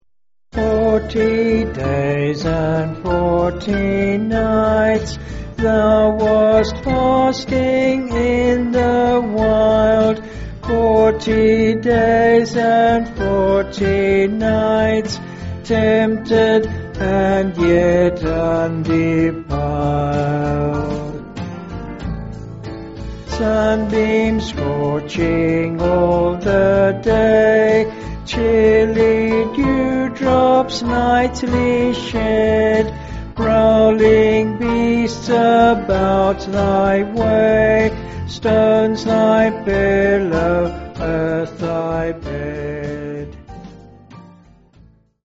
(BH)   6/Dm-Ebm
Vocals and Band